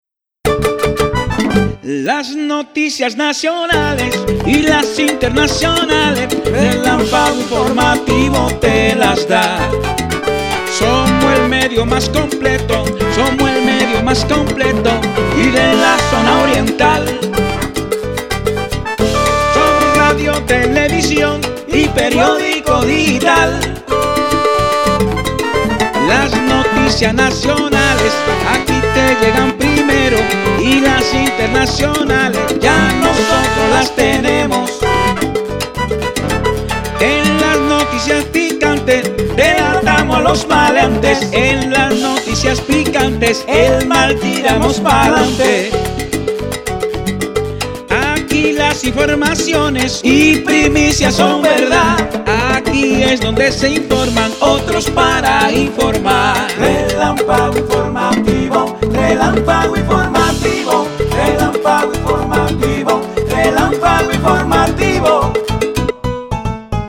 Haz clic en el botón para ir a Relámpago Informativo, pero antes haz clic en la pista de audio para que escuche nuestra recomendación en forma musical.